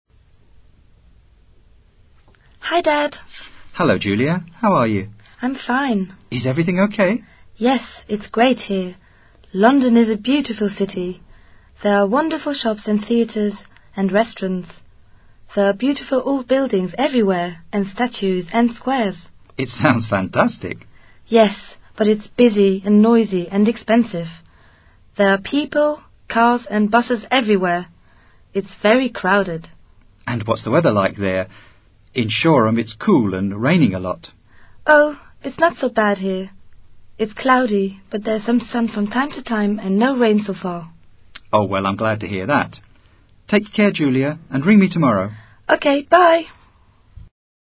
Diálogo útil para trabajar la descripción de ciudades, el uso de adjetivos y vocabulario relativo al tiempo.